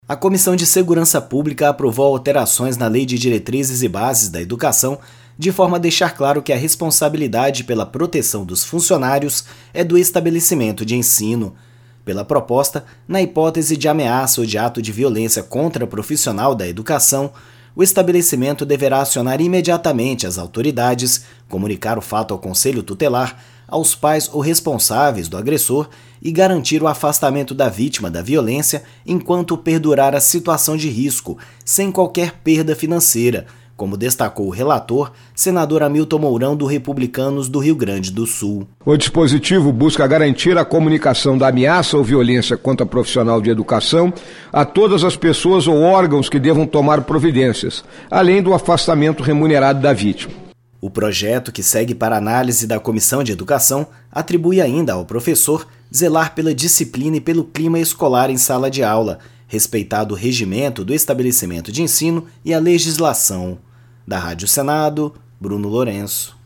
A Comissão de Segurança Pública aprovou nesta terça-feira (27) medidas com o objetivo de aumentar a proteção no ambiente escolar, como procedimentos a serem tomados nos casos de agressões a professores. O relator, senador Hamilton Mourão (Republicanos-RS), diz que o objetivo é garantir a comunicação da ameaça ou violência contra profissional de educação a todas as pessoas ou órgãos que devam tomar providências, além do afastamento remunerado da vítima.